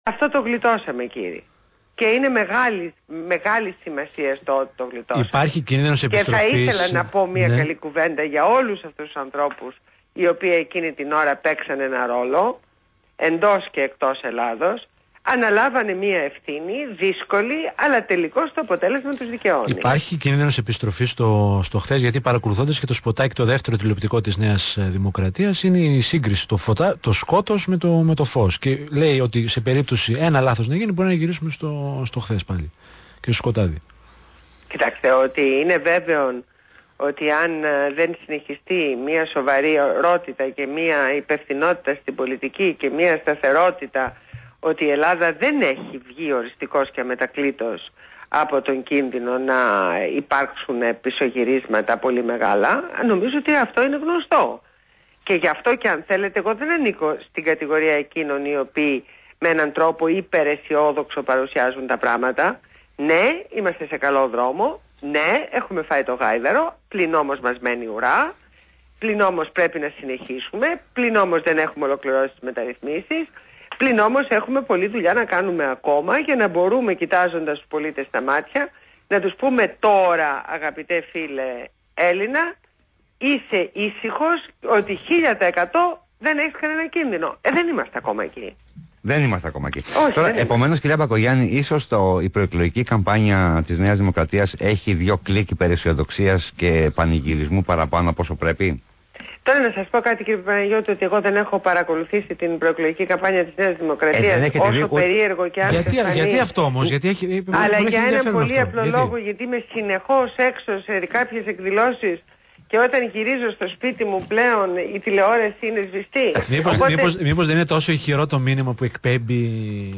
Ακούστε τη συνέντευξη της Ντόρας Μπακογιάννη στο ραδιόφωνο ΒΗΜΑfm.